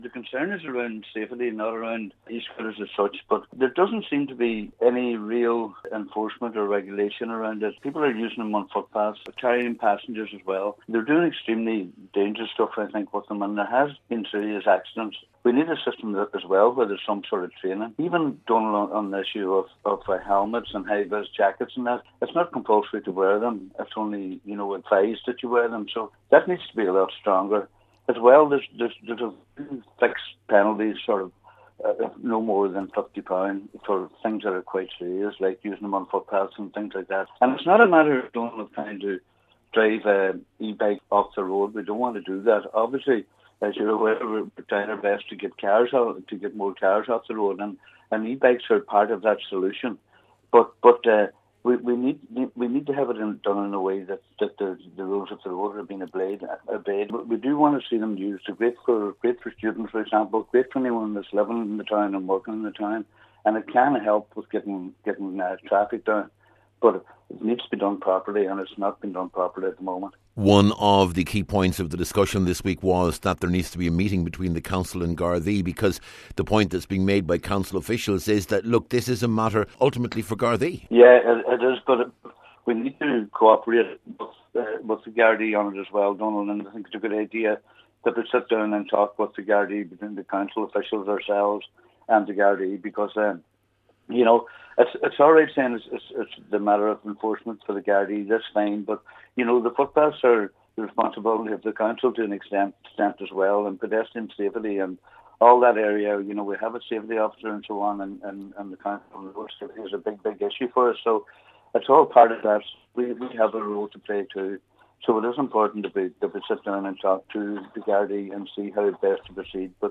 Cllr Kavanagh says discussions are necessary, to ensure that what could be a very positive resource in cutting down traffic congestion is used properly………..